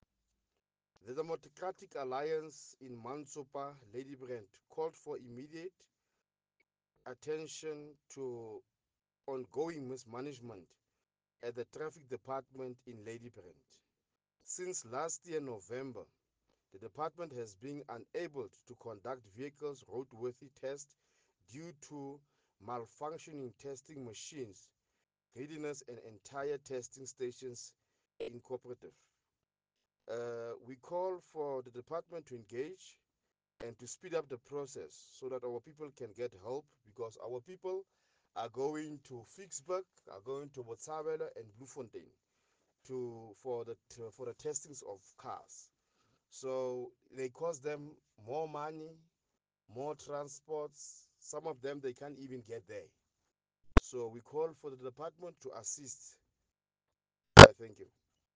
Afrikaans soundbites by Cllr Nicky van Wyk.